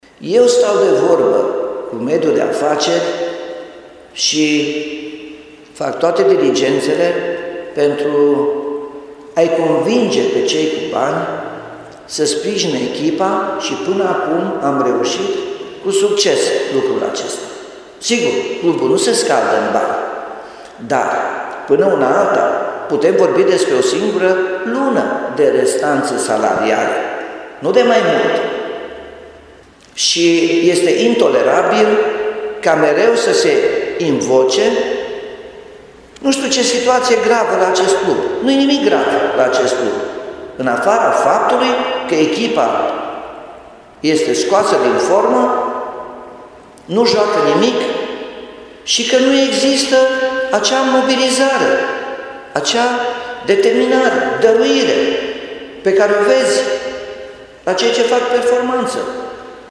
Edilul a menționat într-o conferință de presă, al Primărie, că situația nu e atât de gravă precum antrenorul Ionuț Popa a lăsat să se înțeleagă la finalul înfrângerii cu Concordia Chiajna: